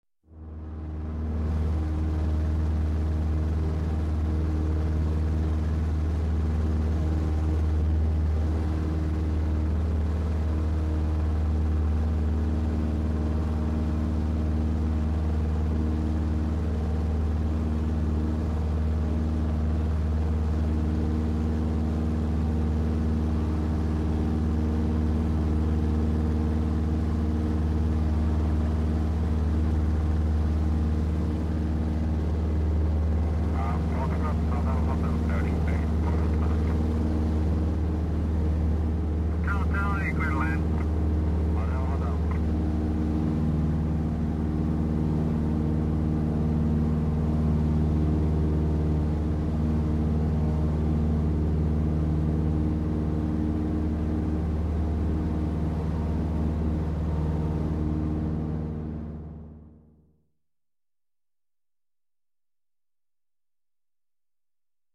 На этой странице собраны звуки военных самолетов разных типов: от рева реактивных двигателей до гула винтовых моделей.
Винтовой самолет звук внутри